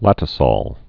(lătə-sôl, -sŏl)